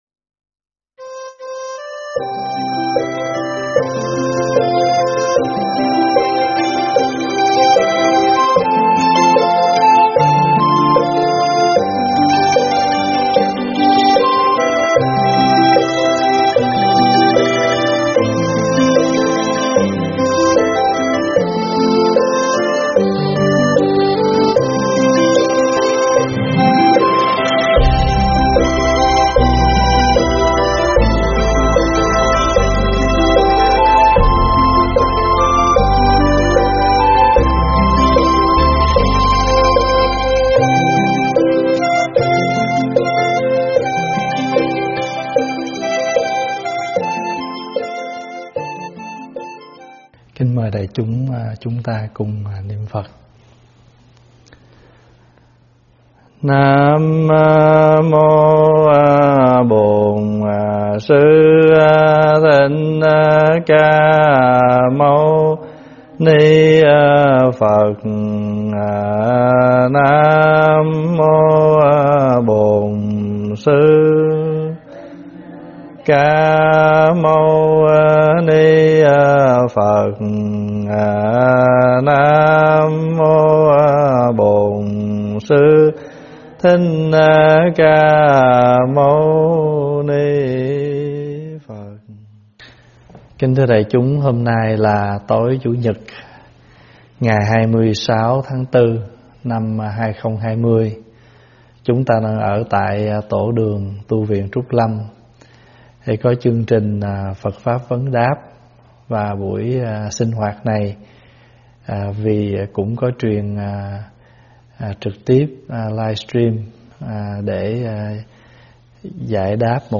thuyết pháp Hành Hương Thập Tự
giảng tại Tv.Trúc Lâm